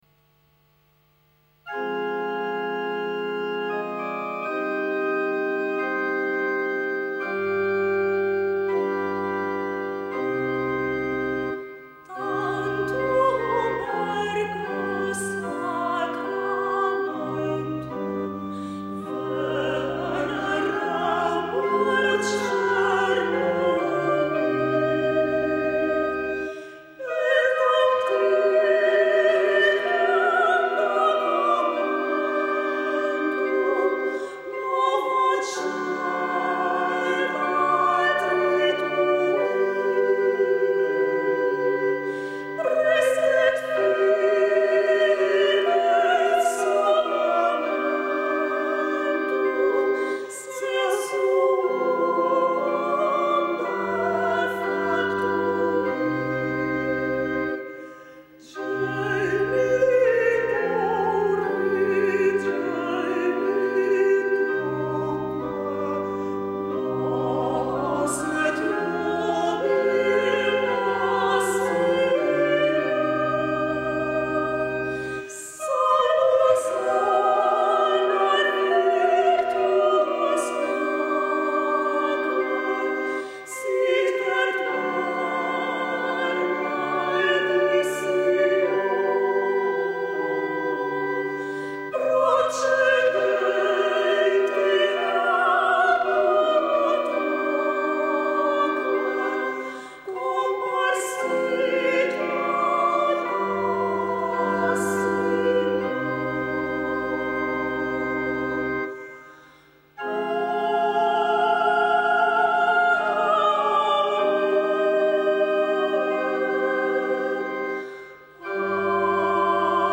Música Sacra
A música sacra realiza-se durante rituais divinos (por exemplo, procissões) e em espaços religiosos (igrejas, conventos, entre outros).
Os instrumentos habituais são as vozes, o órgão de tubos, podendo incluir, por vezes, outros instrumentos.
tantum ergo.mp3